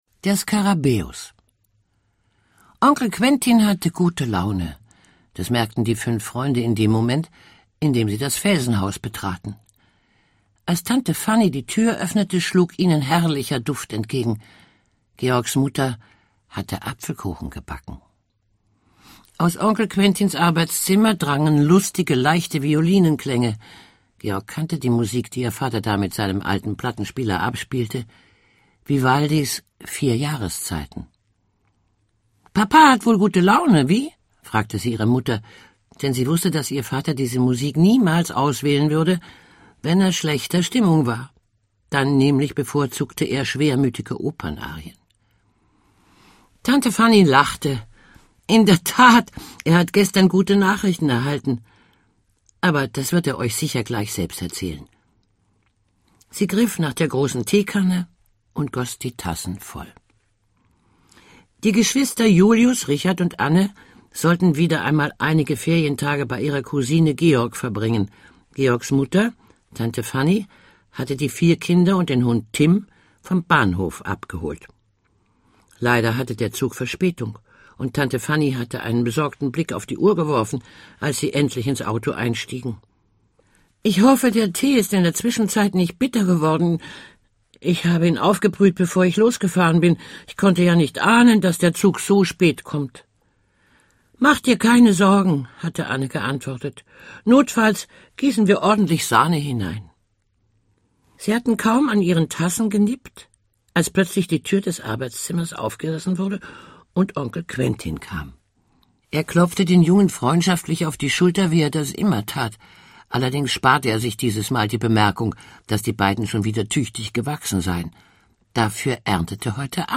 Rosemarie Fendel (Sprecher)
Schlagworte Abenteuer für Kinder • Hörbuch für Kinder/Jugendliche • Hörbuch für Kinder/Jugendliche (Audio-CD) • Hörbuch; Hörspiel für Kinder/Jugendliche • Kinderklassiker • Kinderkrimi • Krimis/Thriller; Kinder-/Jugendliteratur • Krimis/Thriller; Kinder-/Jugendliteratur (Audio-CDs)